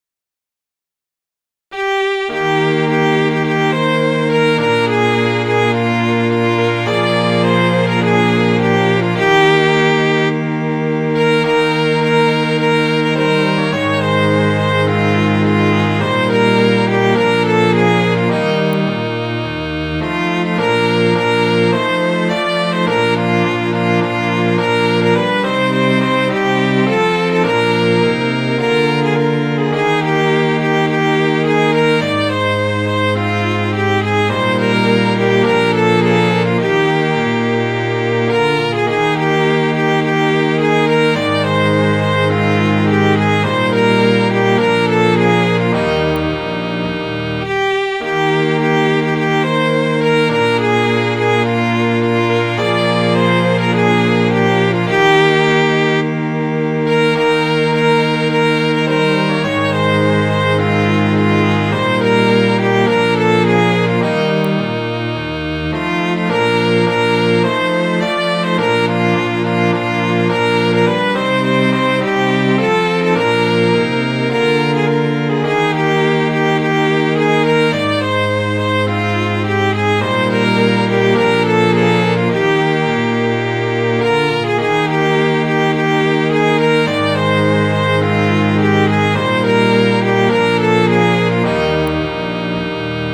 Midi File, Lyrics and Information to Ben Bolt